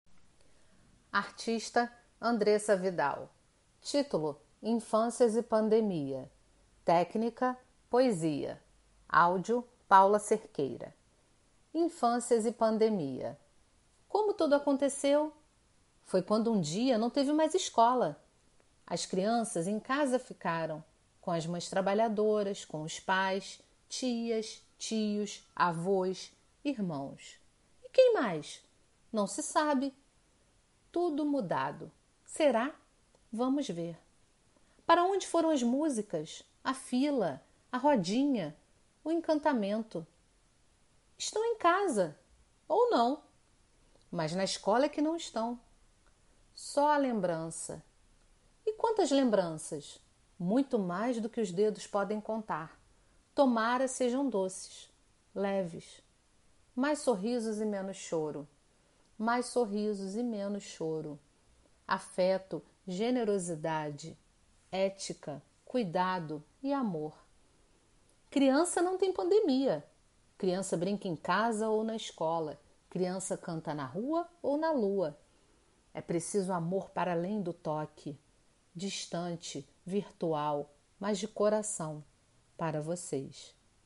Poesia com voz humana